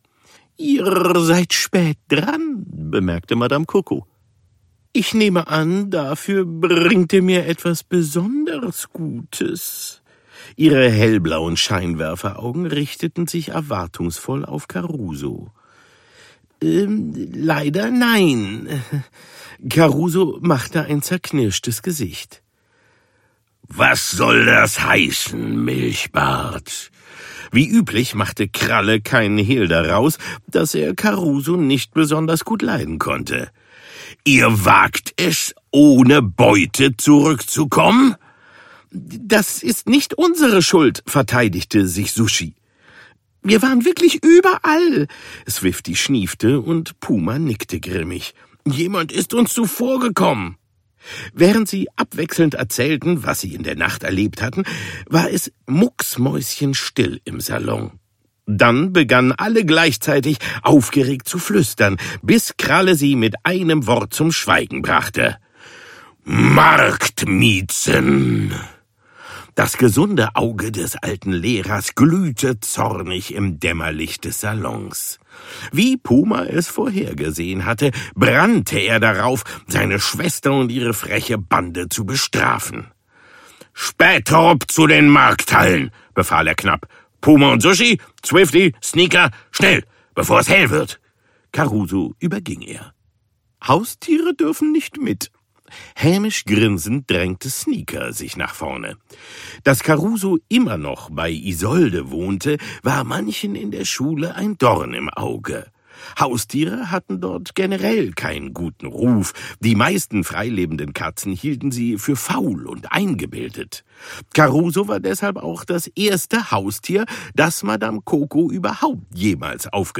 Oliver Kalkofe (Sprecher)
Ungekürzte Lesung, Lesung mit Musik